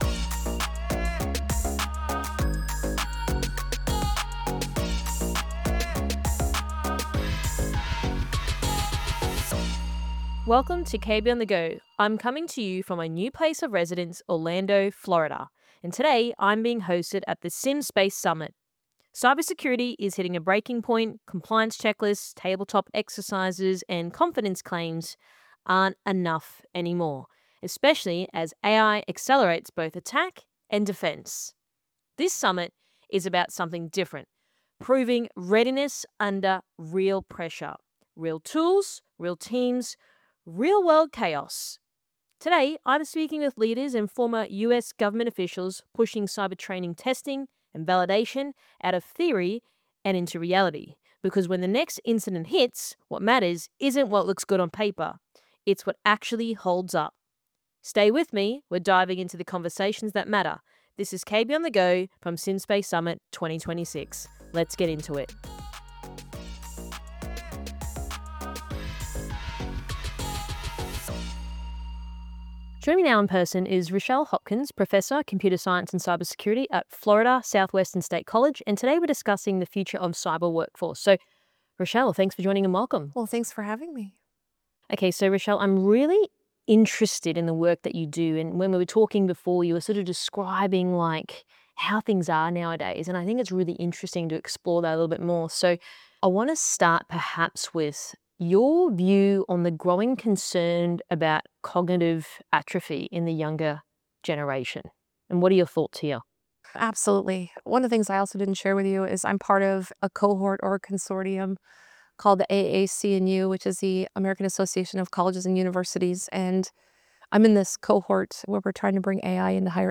From the SimSpace Summit 2026